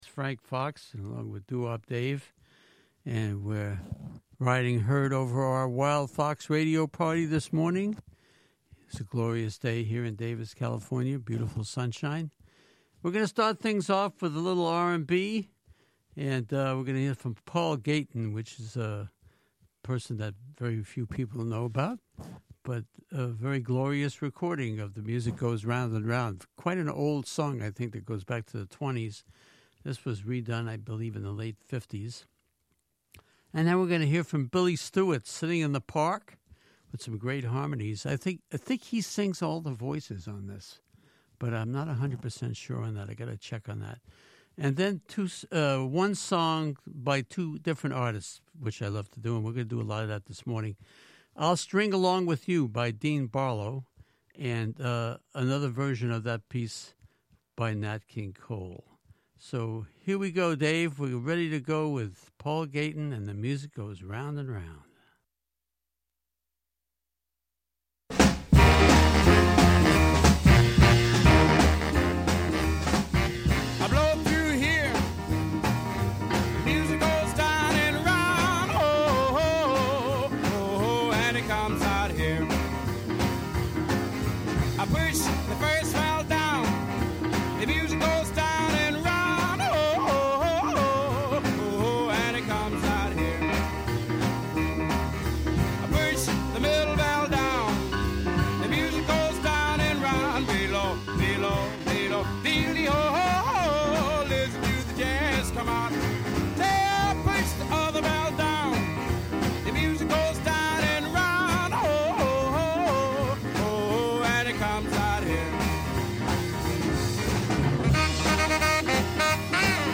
Tune in for a wide variety of roots music including doo-wop, gospel, jazz, blues, rock 'n' roll, Latin, African, and hillbilly/country bluegrass from the early 1920s to contemporary times.